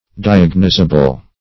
diagnosable - definition of diagnosable - synonyms, pronunciation, spelling from Free Dictionary
diagnosable.mp3